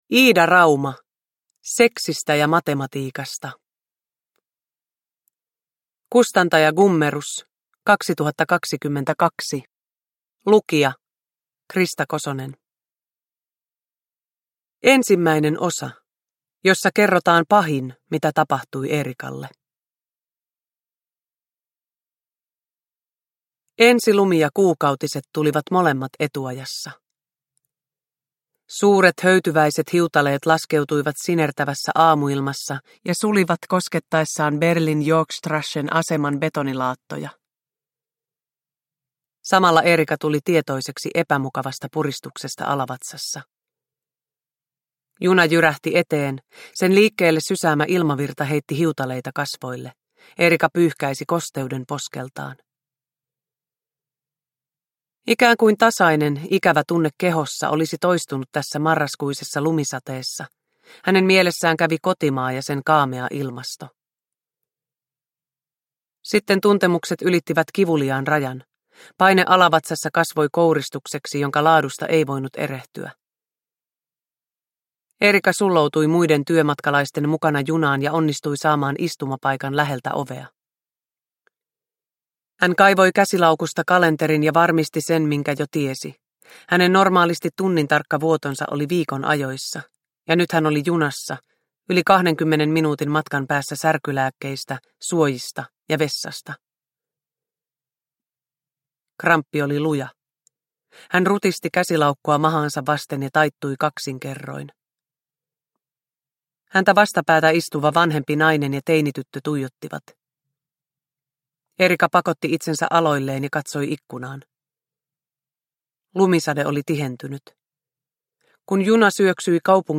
Seksistä ja matematiikasta – Ljudbok – Laddas ner
Uppläsare: Krista Kosonen